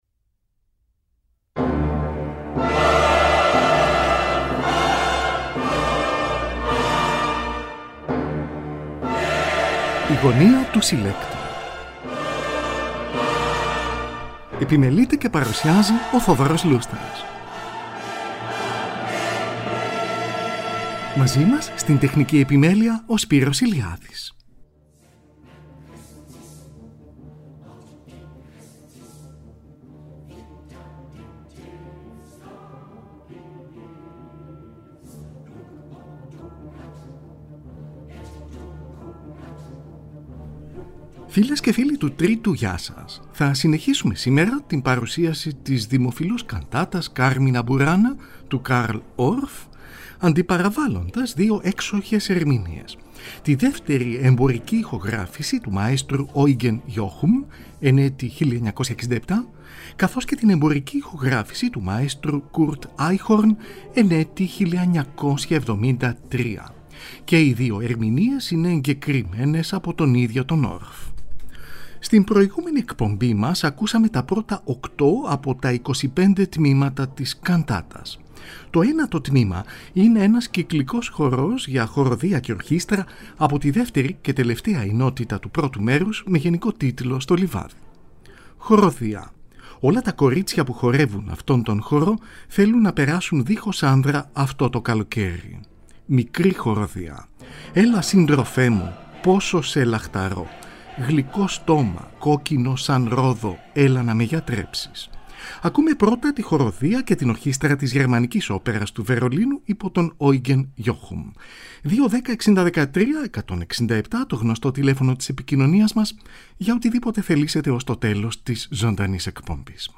Η ΣΚΗΝΙΚΗ ΚΑΝΤΑΤΑ